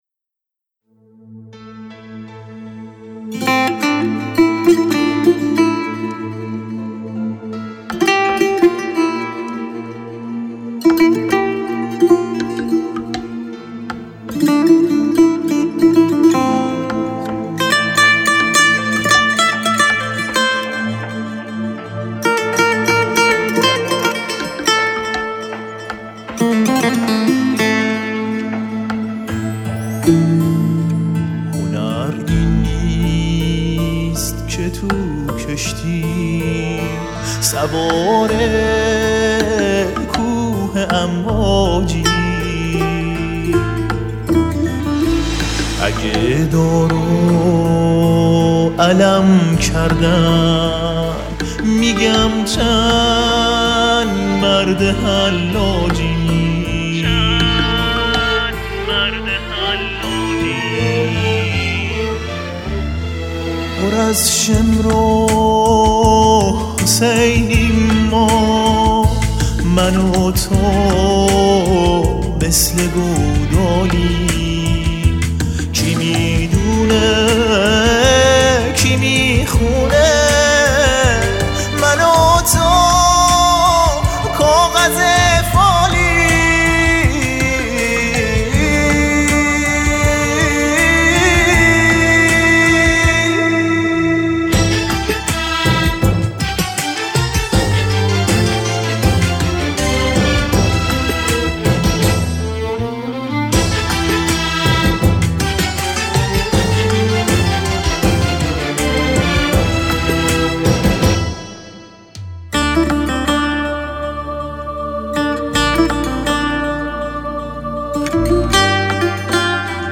ویلون و ویلون آلتو
سه تار
پیانو و سازهای الکترونیک